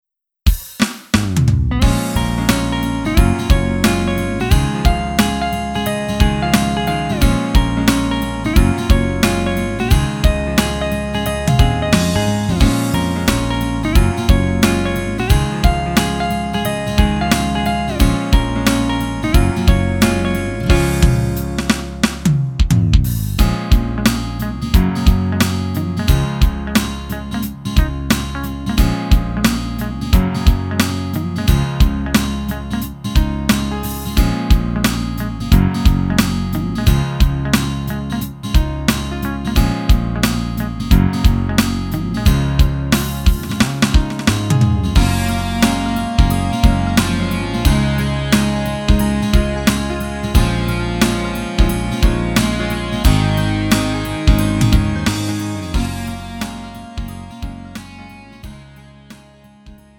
음정 원키 4:43
장르 구분 Lite MR